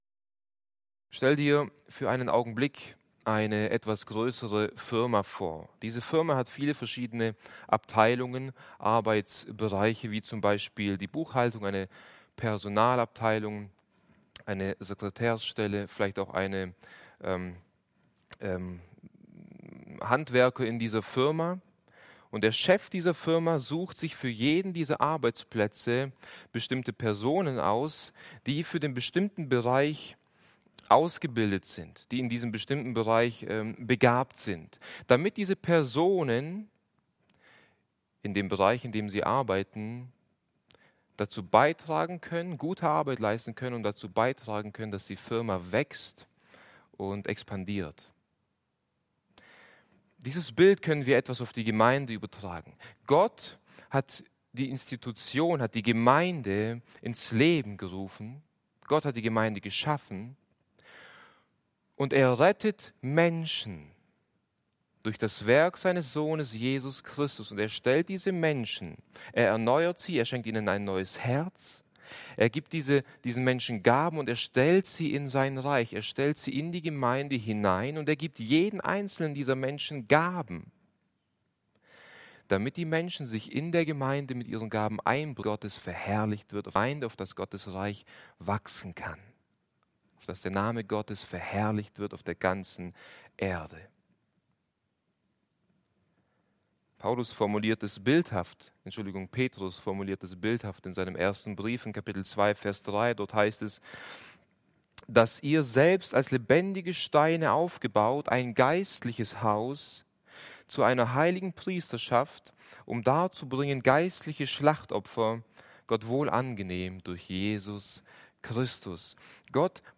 Dienstart: Bibelstunden